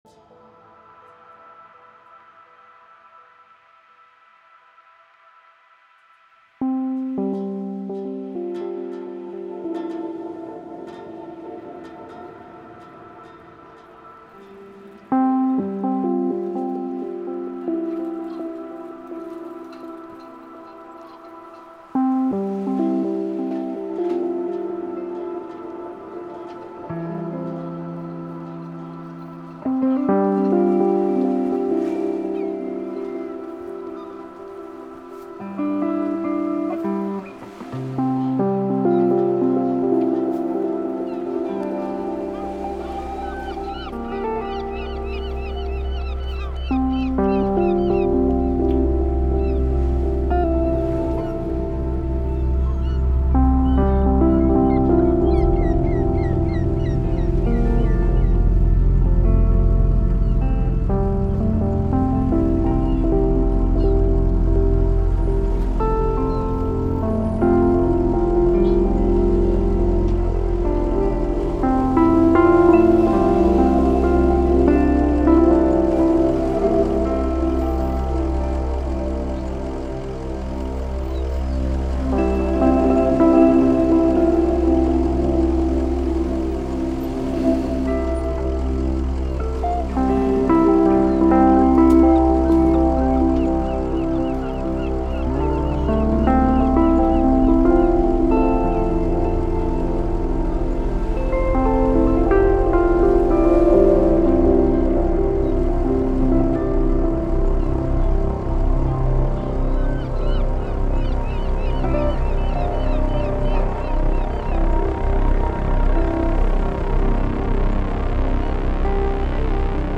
He mixes electronic and organic instruments to craft unique musical universes.